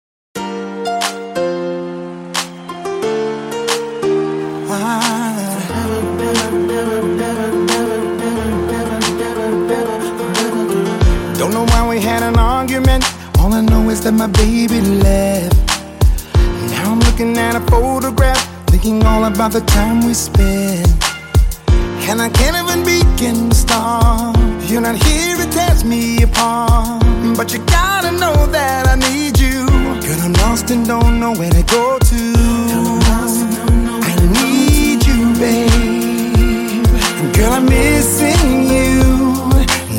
соул , rnb